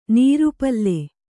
♪ nīru palle